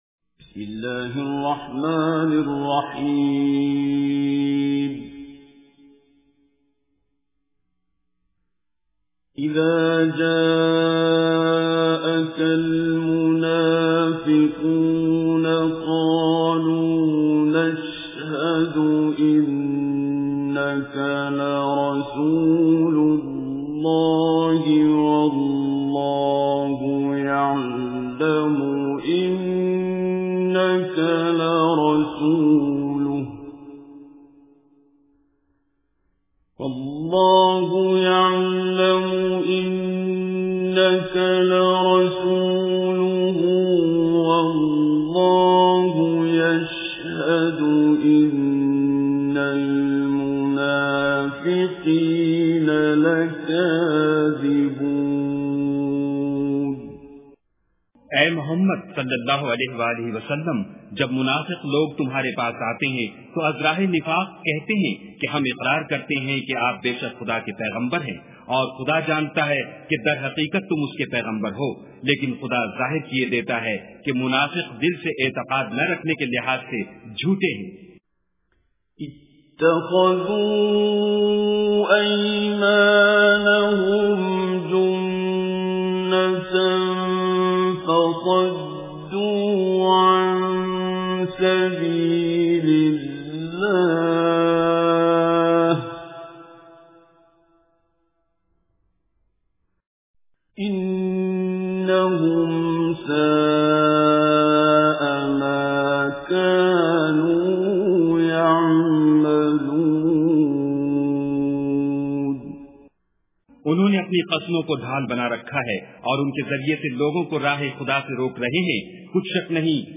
Surah Munafiqun Recitation with Urdu Translation
Surah Al-Munafiqun is 63 Surah or chapter of Holy Quran. Listen online and download mp3 tilawat / recitation of Surah Al-Munafiqun in the beautiful voice of Qari Abdul Basit As Samad.